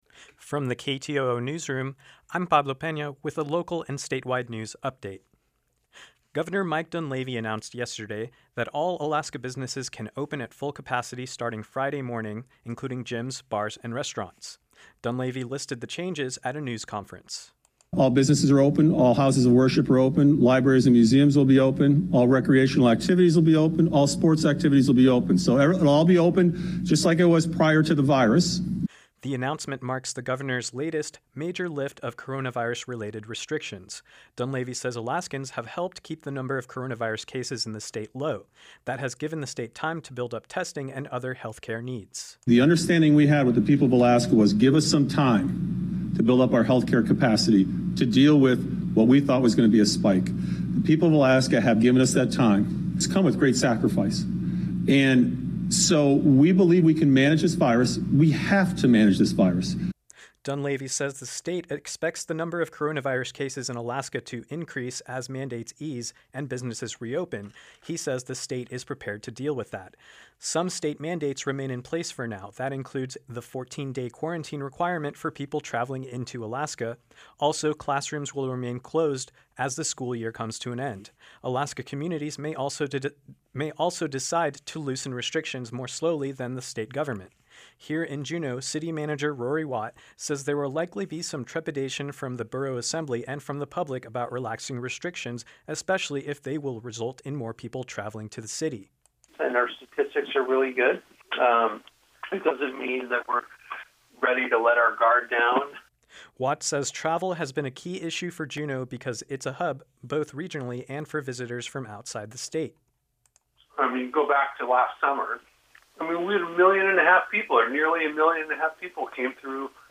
Newscast — Wednesday, May 20, 2020